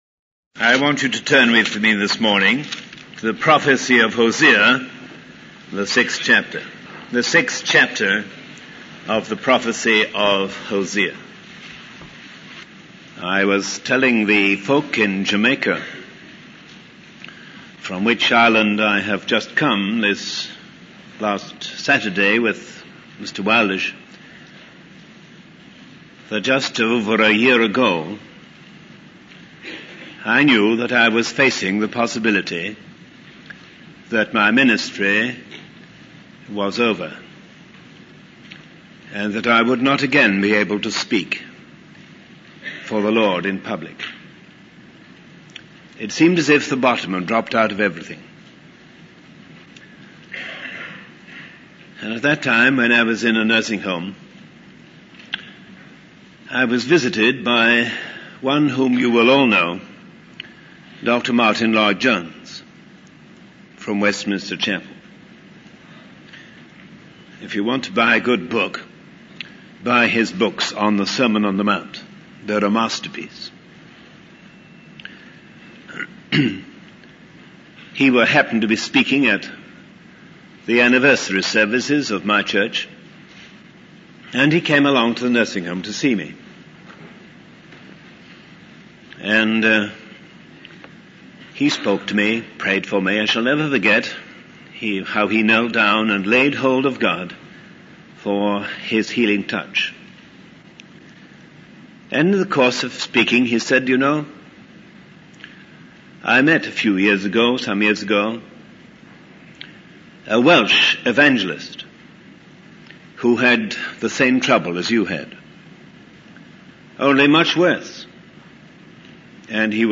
In this sermon, the preacher discusses the struggle of God for the soul of a nation, using the book of Hosea as a reference. He emphasizes the importance of turning to the Lord and crying out to Him in times of difficulty, rather than relying on worldly solutions.